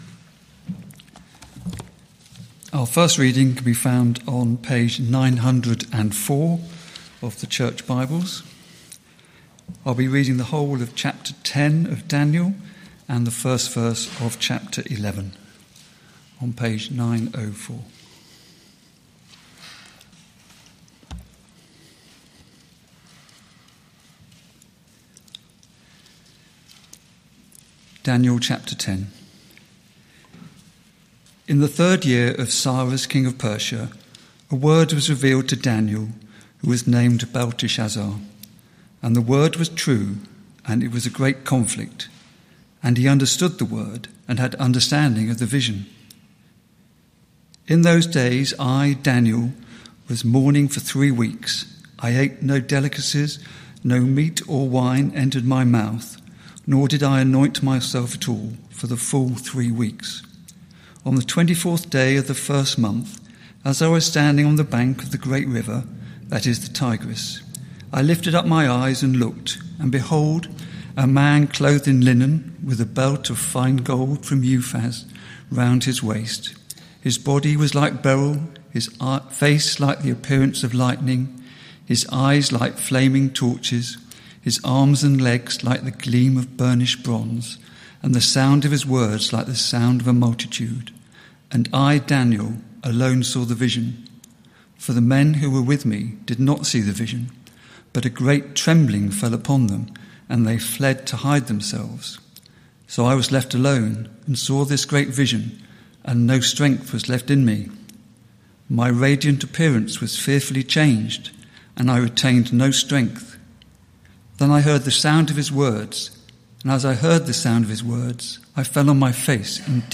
Media for Evening Meeting on Sun 20th Jul 2025 18:00 Speaker
Sermon Search media library...